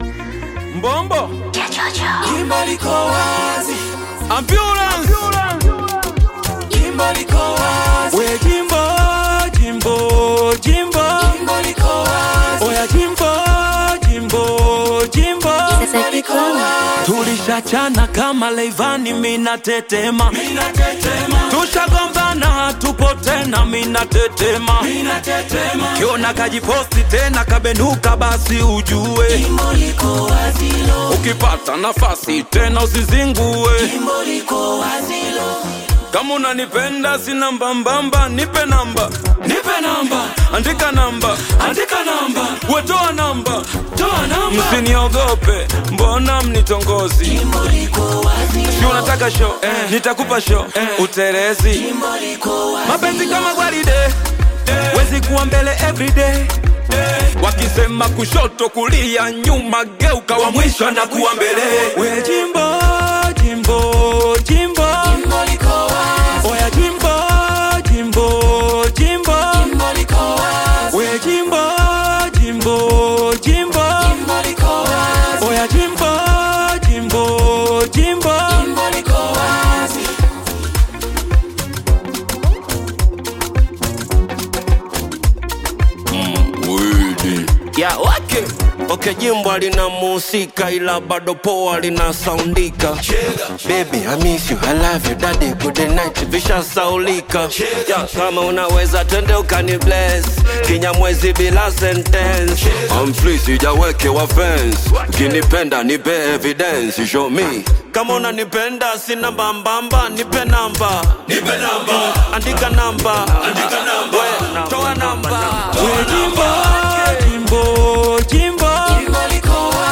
Bongo Flava
Bongo Flava song